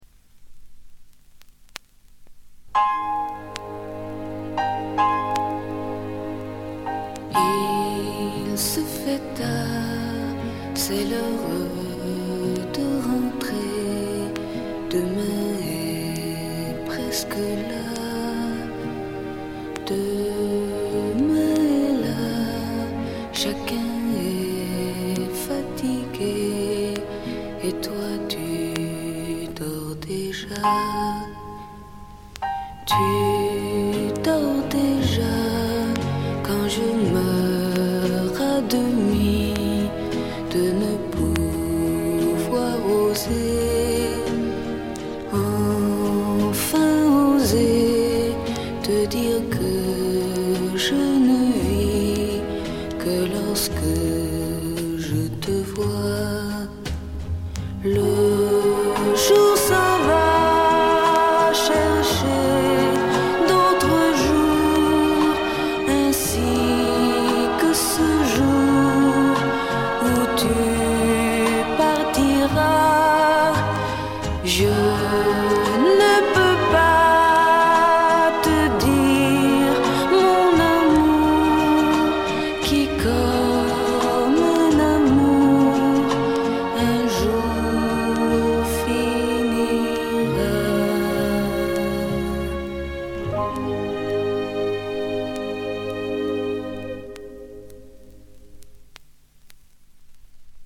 モノラル盤。
試聴曲は現品からの取り込み音源です。